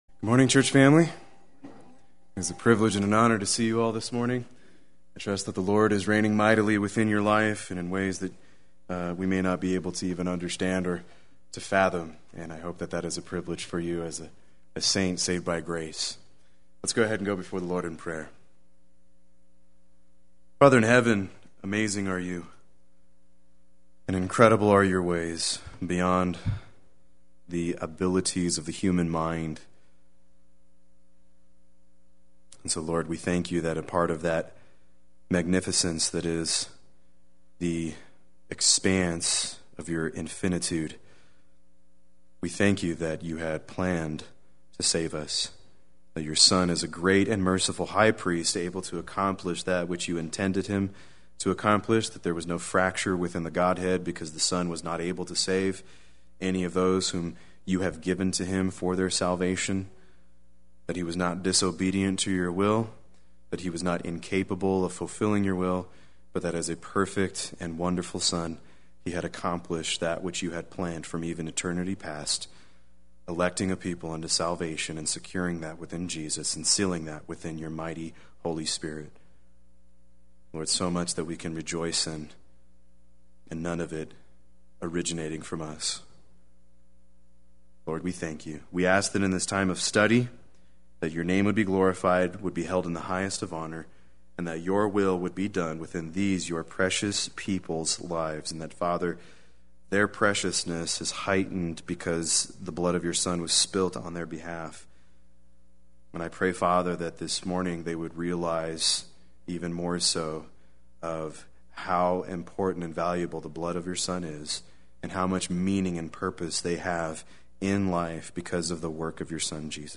Play Sermon Get HCF Teaching Automatically.
Part 2 Sunday Worship